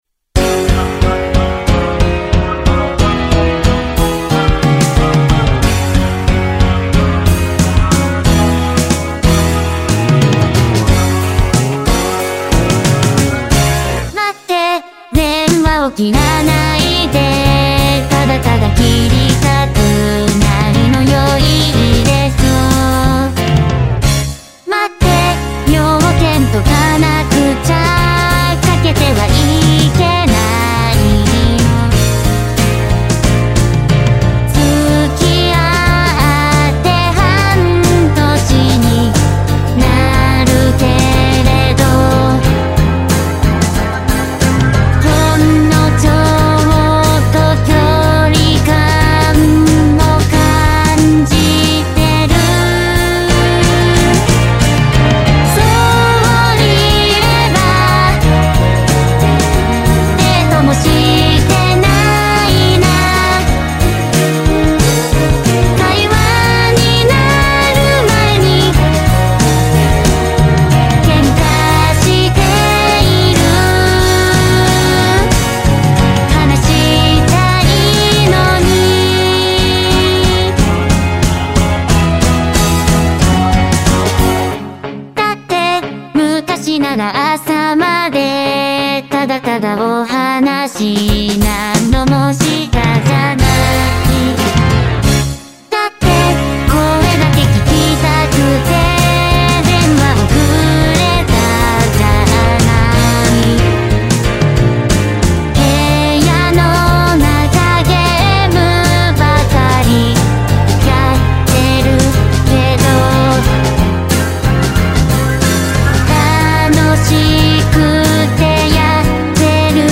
バラード系は感じを出すのが難しいです＞＜